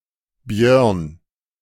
German: [bjœʁn]
De-Björn.ogg.mp3